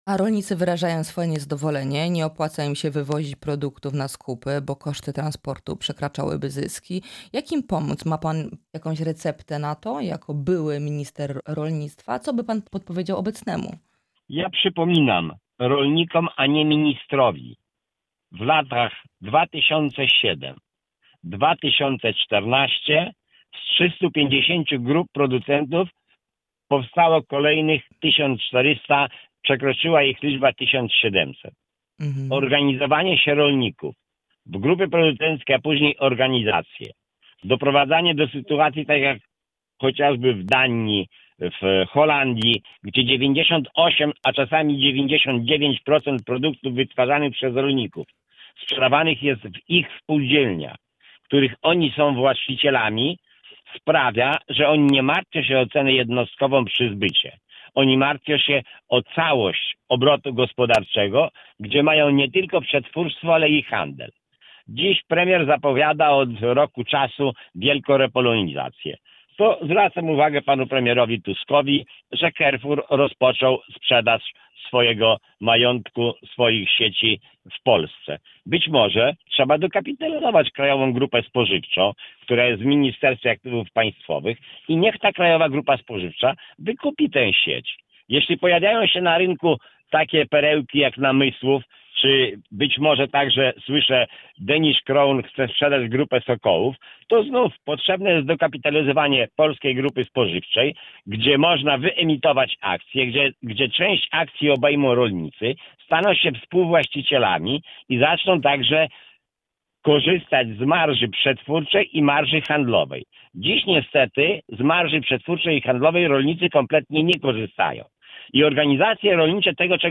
Byłego ministra rolnictwa pytaliśmy o problemy rolników i umowę z Mercosur.